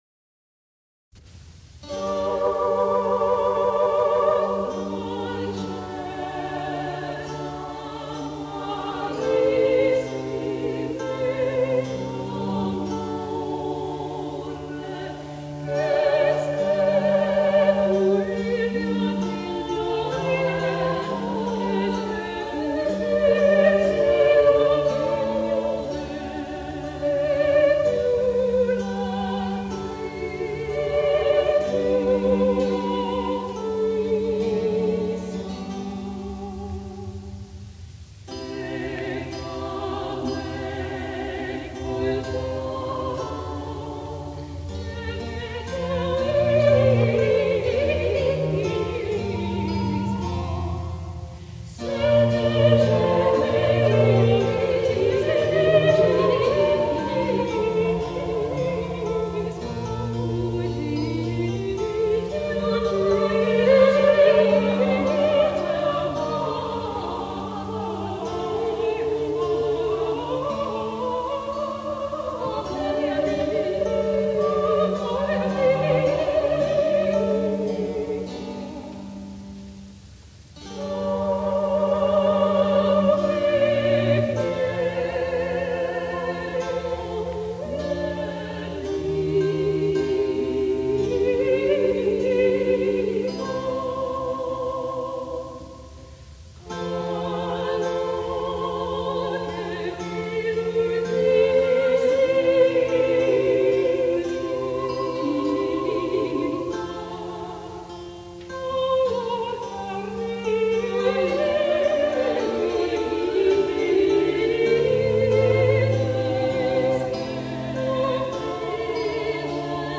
soprano
alto